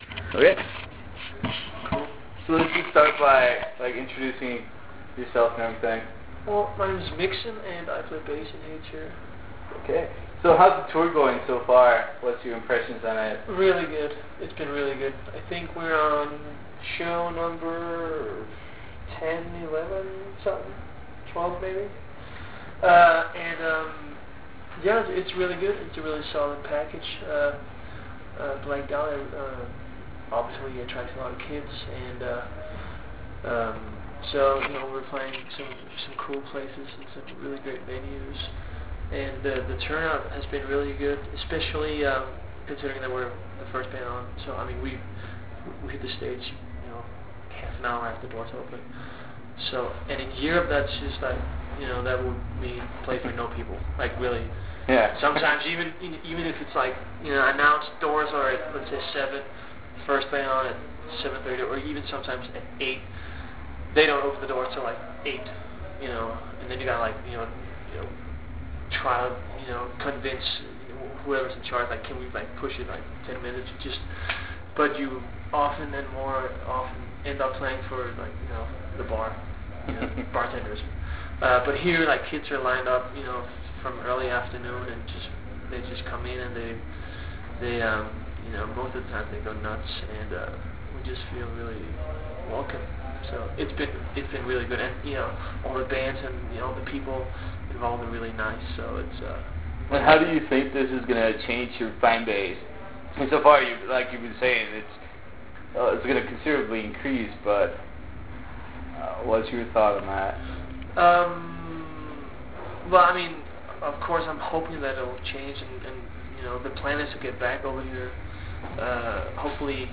During the interrupted interview (some backstage Nazi kept harassing us to leave) we talked about the latest album "To The Nines", the recent departure of the band's vocalist and how/who filled for him last moment for this tour.